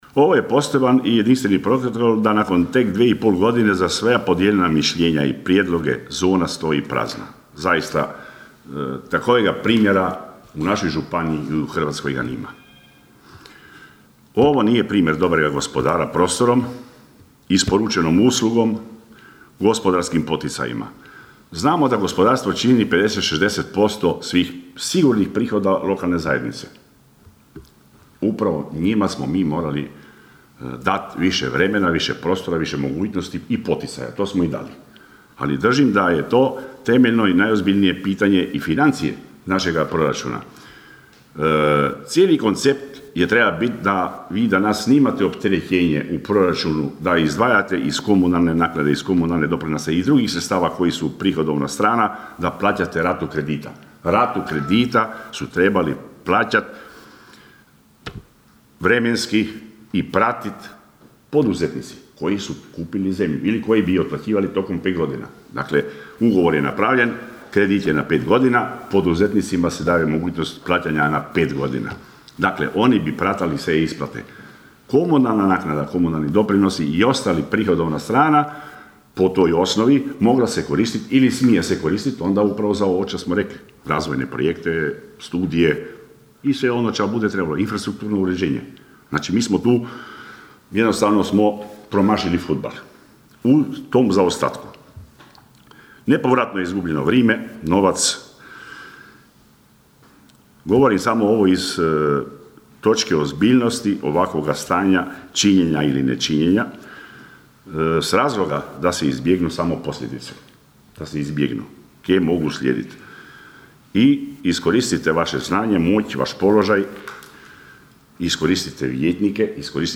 Na prošlotjednoj sjednici Općinskog vijeća Kršana dva su zanimljiva pitanja uputili vijećnik s Nezavisne liste Romana Carića Boris Rogić i nezavisni vijećnik Valdi Runko.
Nezavisni vijećnik Valdi Runko osvrnuo se na problem Proizvodno - poslovne zone Kršan Istok.